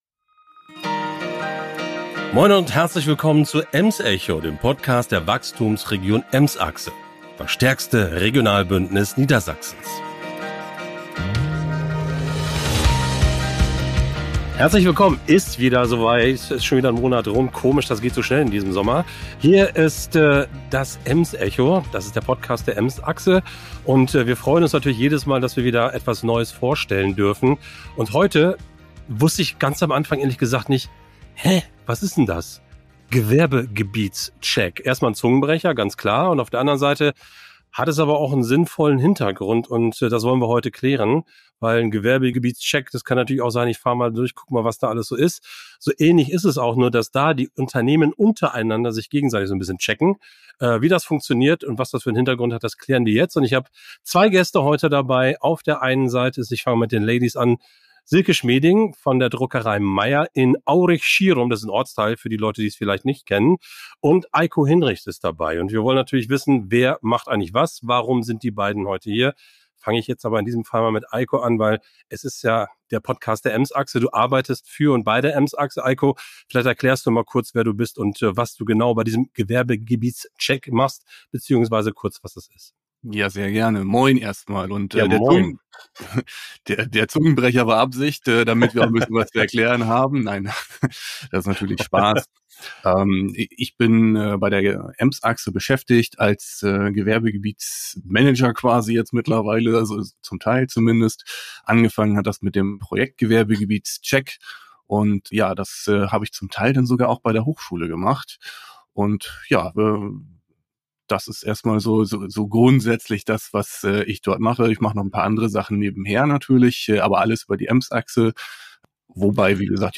Der Talk beleuchtet die Herausforderungen und Erfolge des Gewerbegebietsprojekts sowie die Zukunftsperspektiven